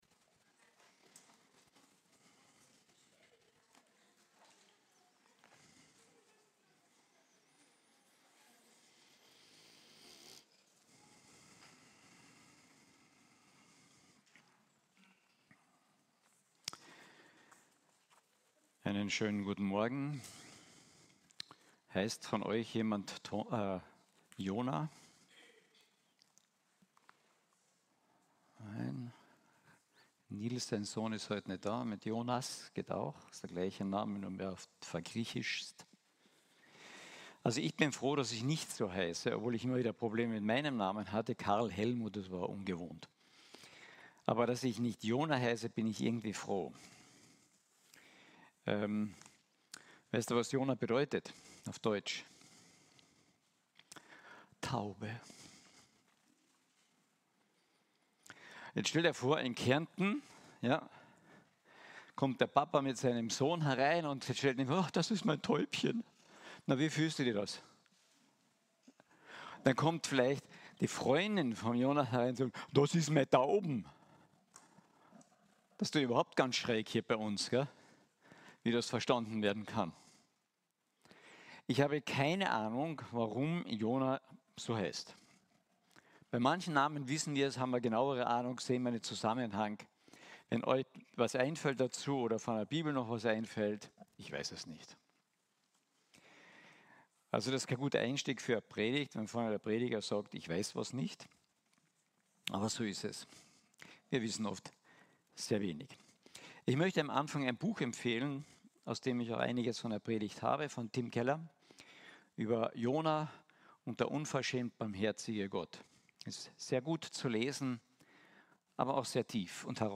Predigten – Archiv – FEG Klagenfurt